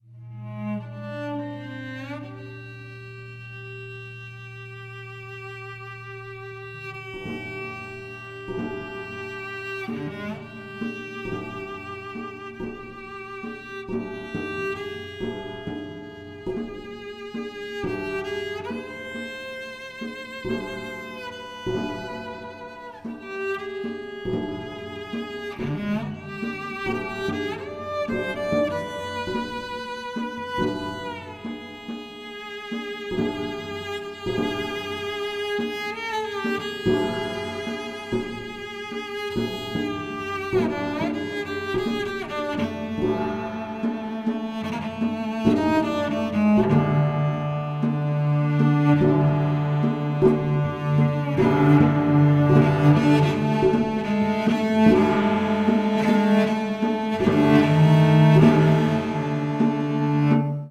Stereo
Korean drums